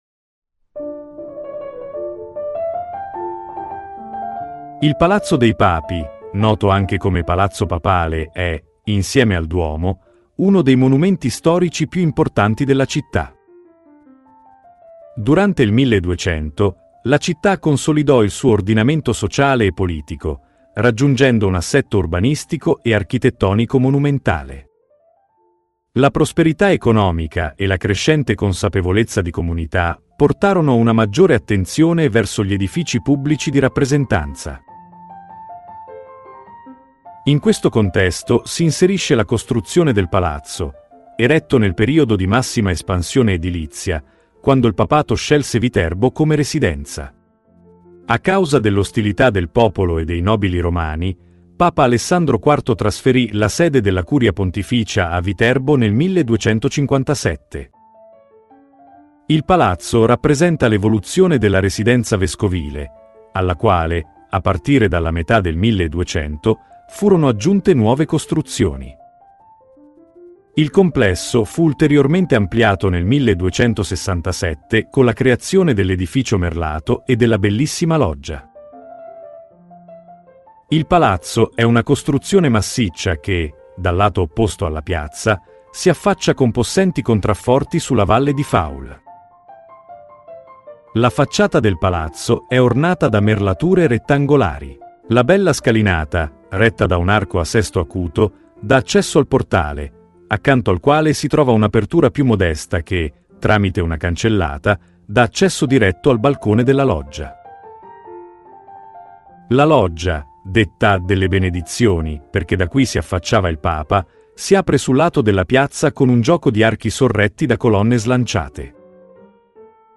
Audioguida Viterbo – Il Palazzo dei Papi